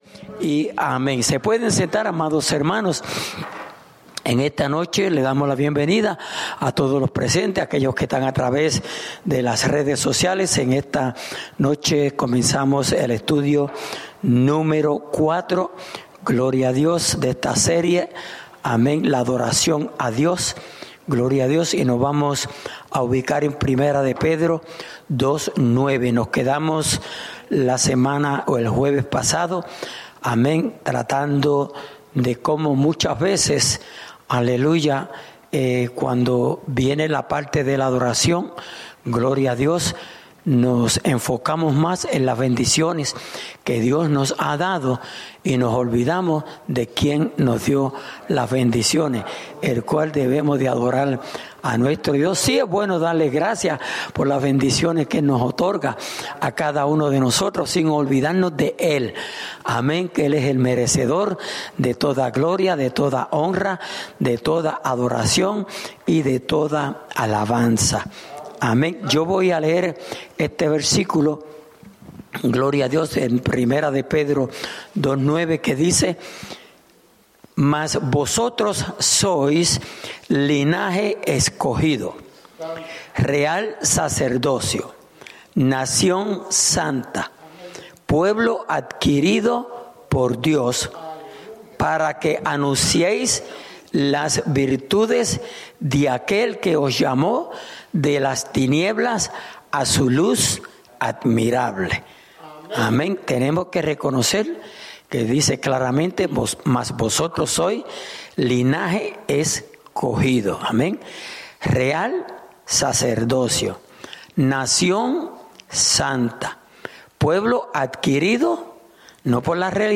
Estudio Bíblico: La adoración ( Parte 4)